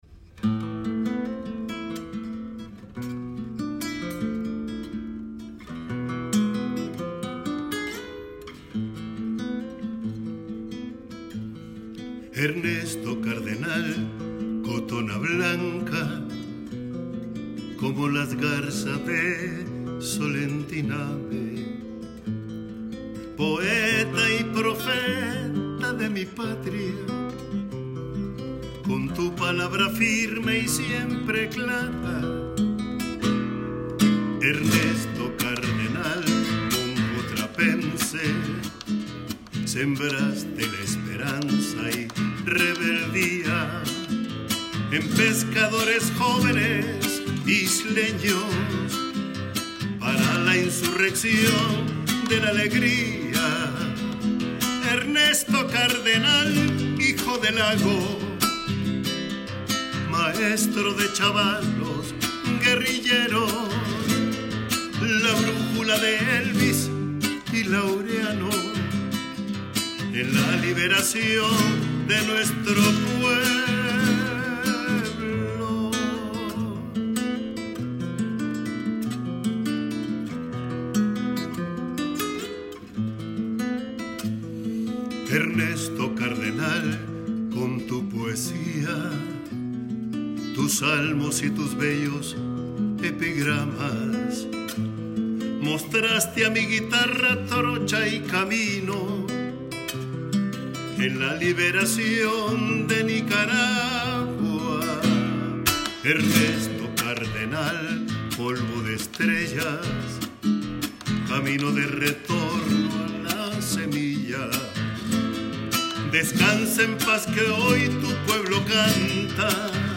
grabada hace pocas horas en San José